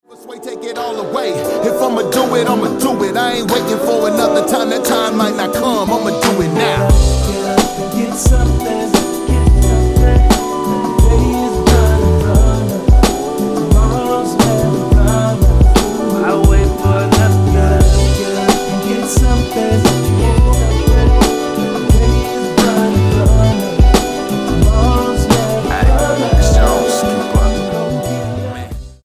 STYLE: Hip-Hop
is a smoother, laid back number with a dark bass twisting in